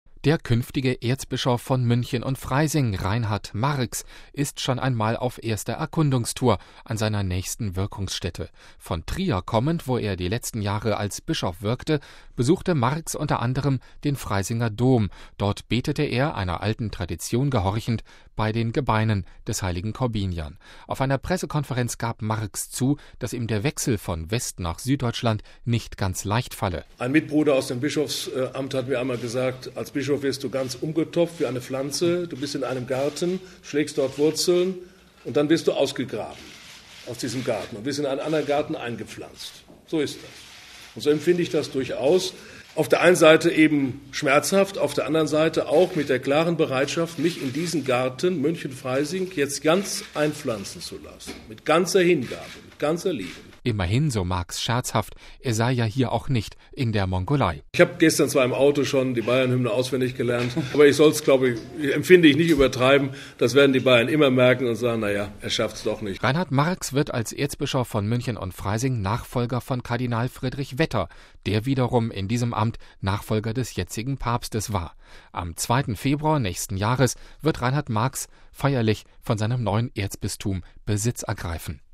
Auf einer Pressekonferenz gab er zu, dass ihm der Wechsel von West- nach Süddeutschland nicht ganz leicht falle.